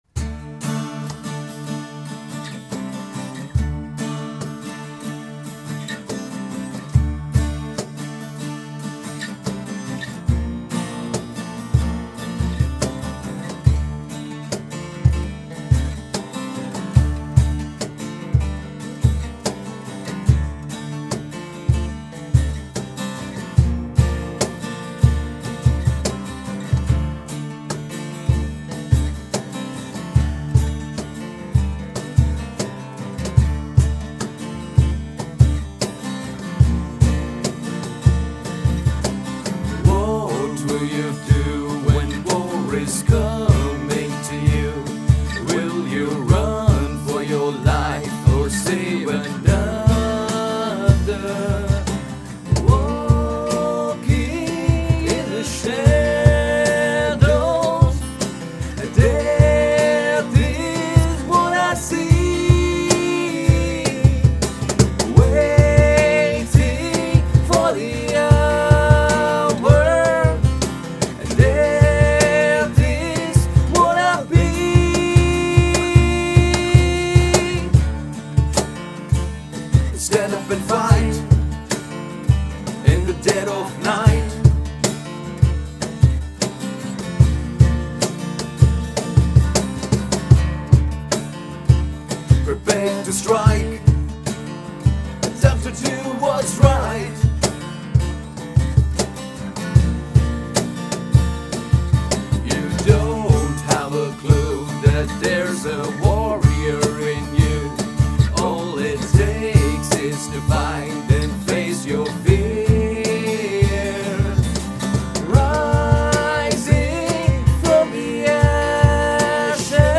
Heavy Metal Band
Unplugged-Version